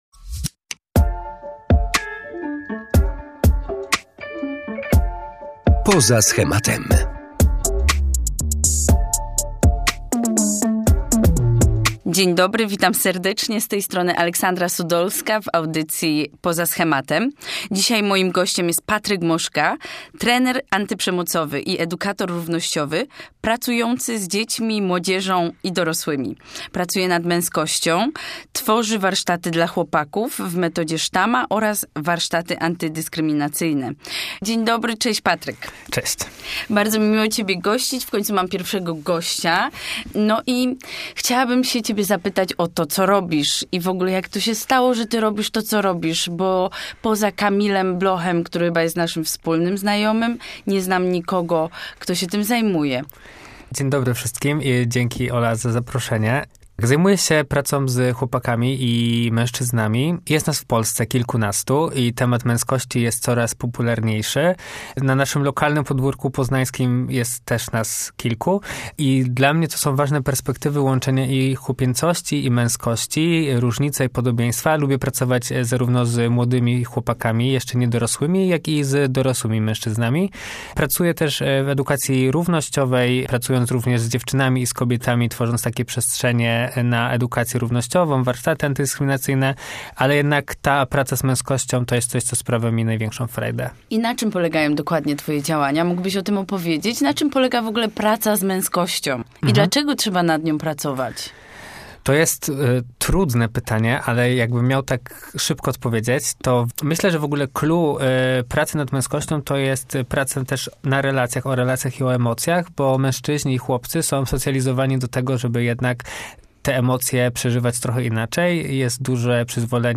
trenerem antyprzemocowym i edukatorem równościowym o współczesnym rozumieniu ,,męskości''.